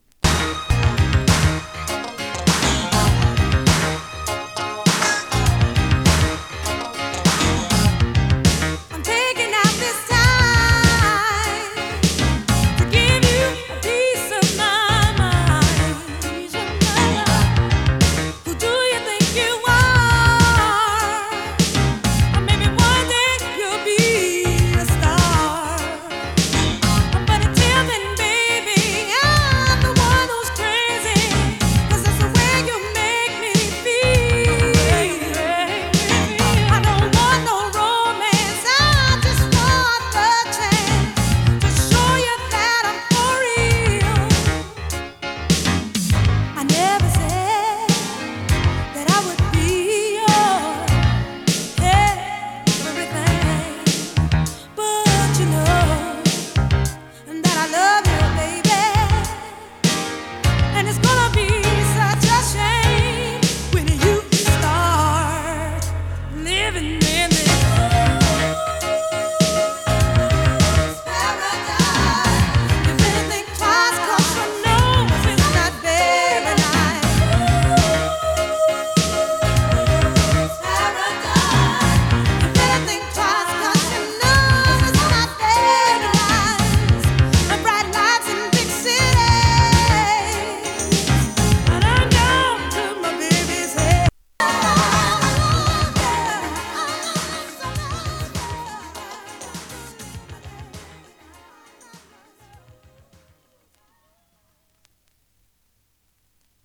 跳ねるピアノとベースが印象的なブラコン・ファンク！